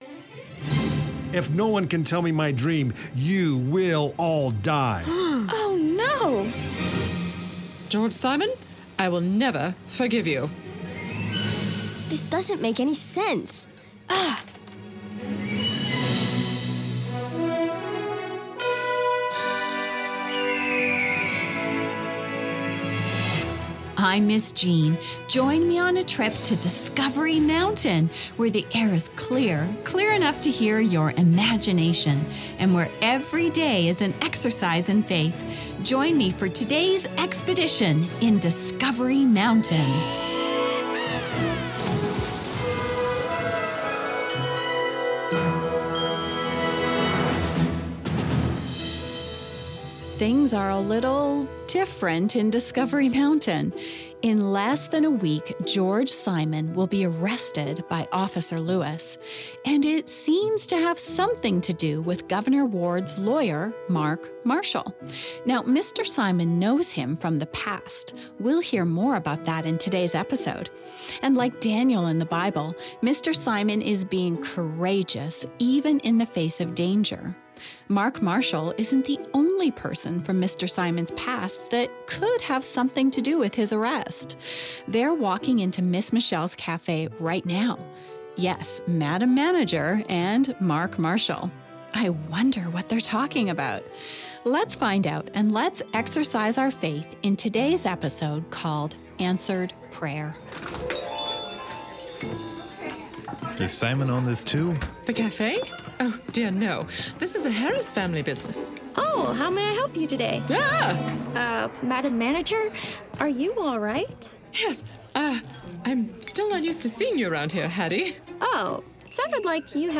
A dramatized audio program where listeners experience adventure, mystery, camp fire songs and, most importantly, get to know Jesus.